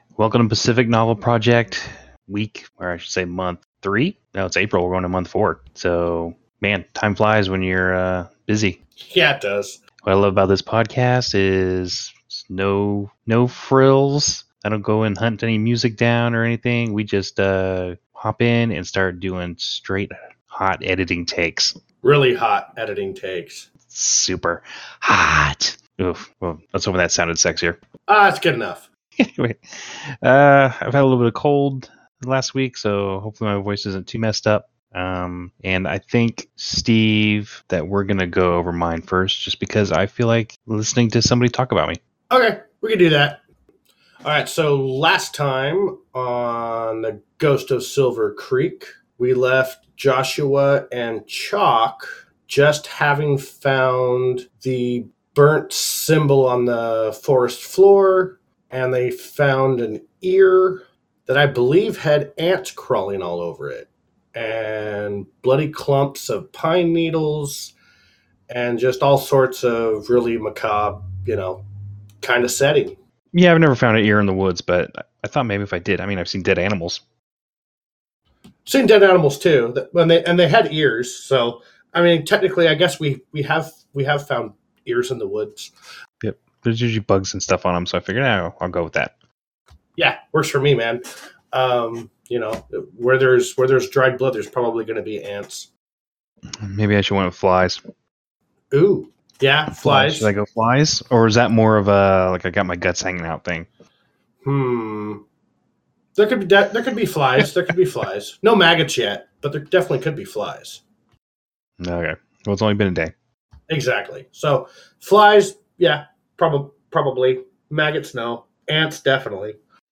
and more southern accents.